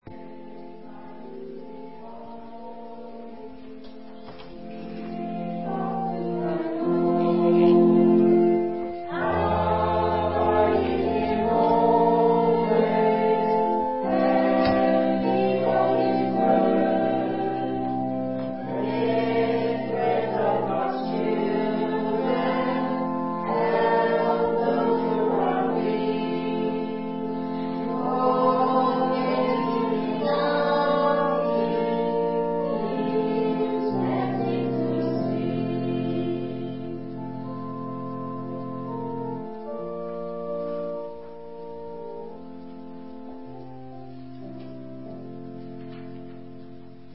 Special performances
btn6 SP-1103-3 Take Time To Be Holy Hymn 500 v1 - Hamilton Congregation